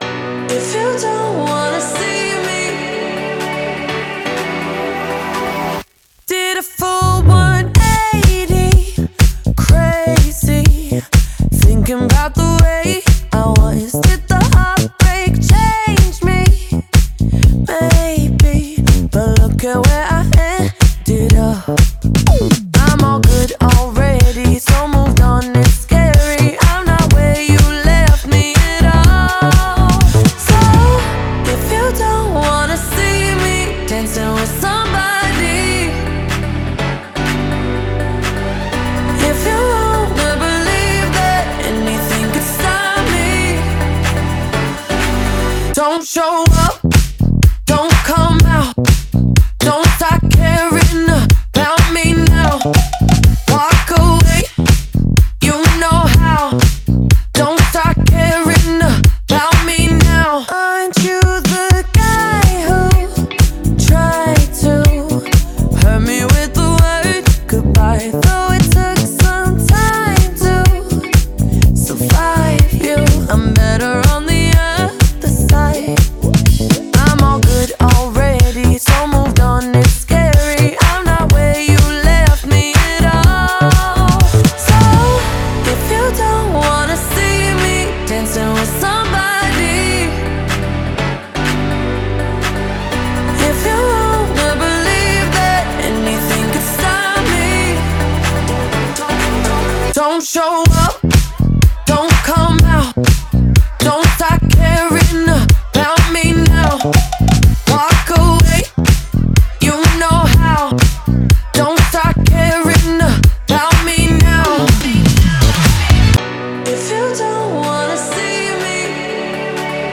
BPM124
MP3 QualityMusic Cut